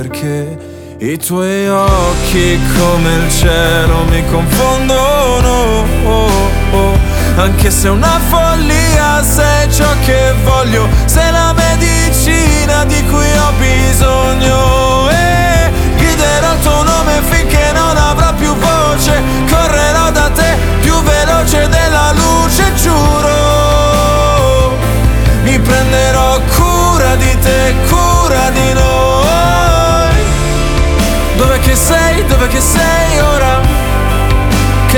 Жанр: Классика
Classical Crossover